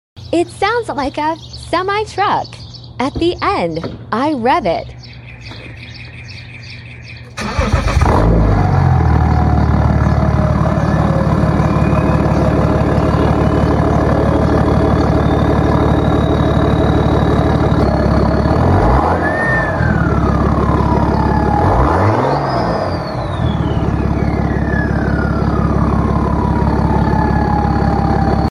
When your "luxury SUV" Mp3 Sound Effect POV: When your "luxury SUV" turns into a Cummins-breathing MONSTER 😈 Straight pipe + Malone 2.9 tune on my 2012 X5 35d deleted the grocery-getter sound FOREVER!